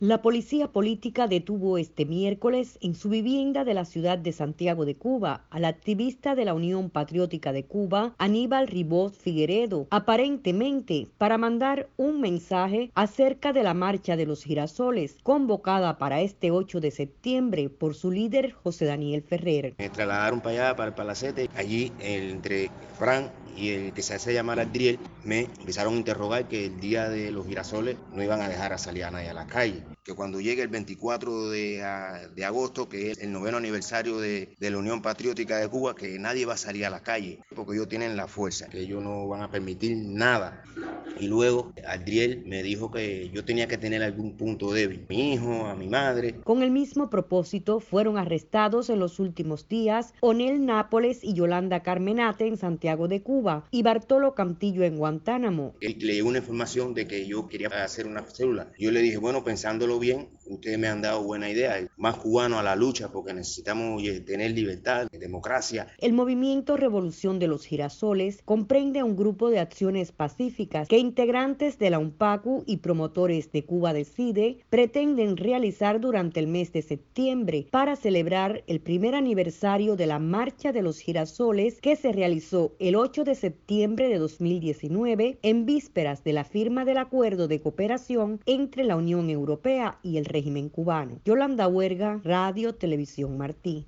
Girasoles por la democracia. Una entrevista